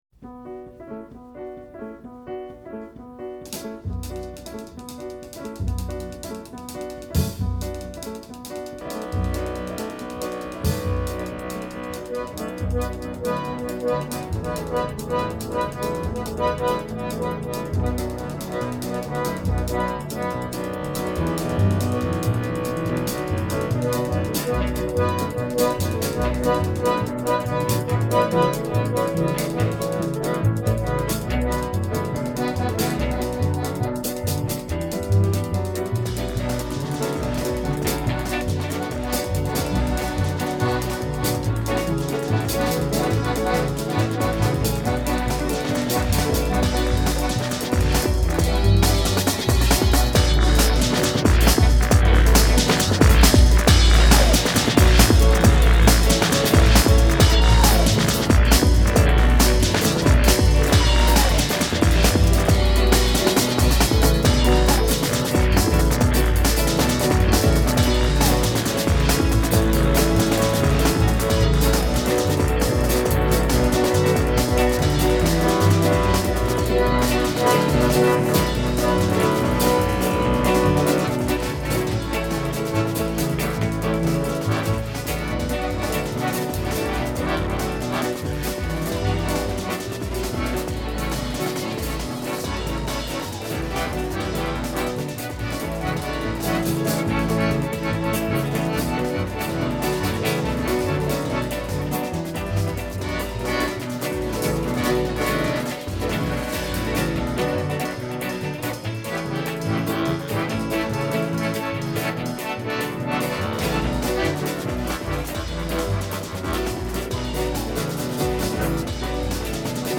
myself playing accordion + vocalising
en Accordion